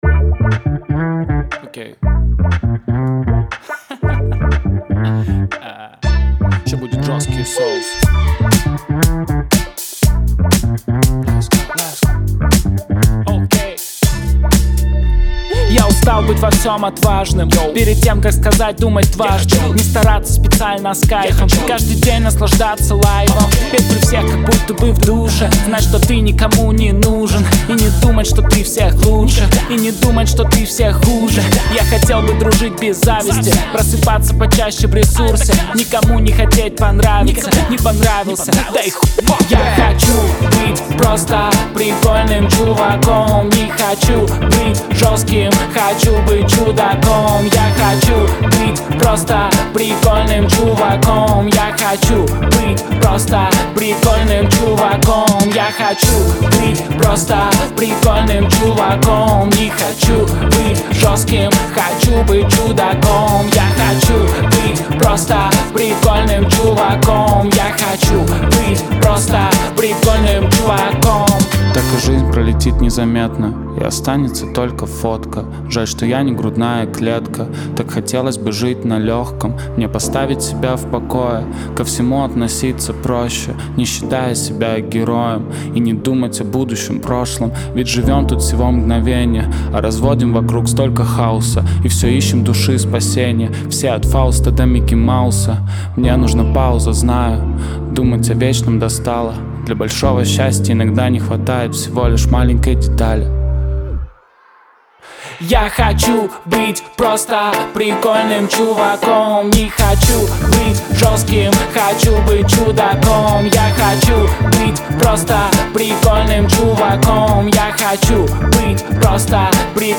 весёлая музыка